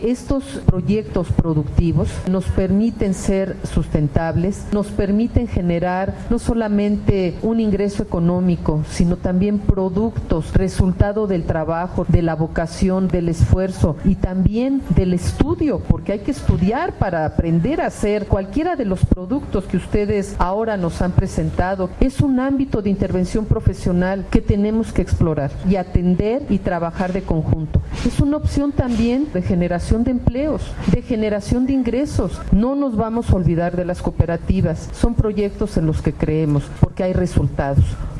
Con un “Sí, protesto”, 214 egresados de la Escuela Nacional de Trabajo Social, culminaron sus estudios de licenciatura el 24 de septiembre, en el auditorio Dr. Raoul Fourier Villada, de la Facultad de Medicina de la UNAM que fue el escenario para llevar a cabo la ceremonia de toma de protesta de titulados durante el 2013.